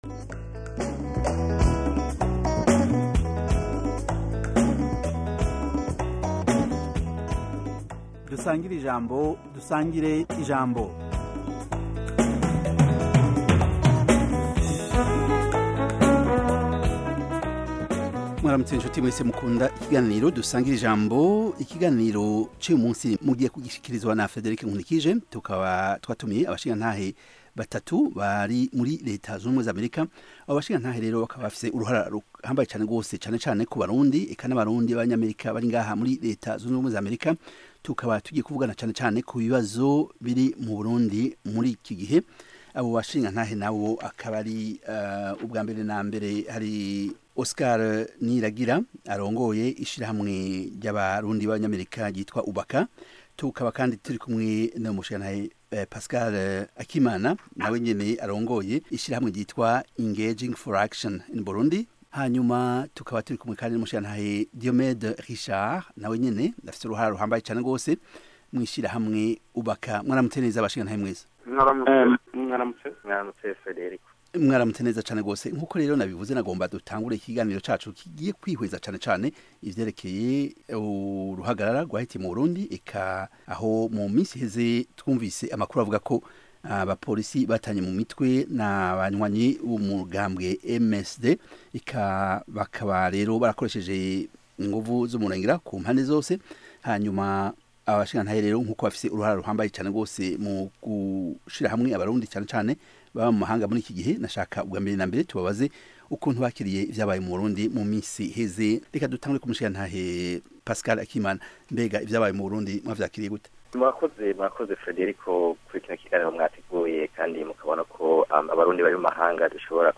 Dusangire-ijambo - Panel discussion and debate on African Great Lakes Region, African, and world issues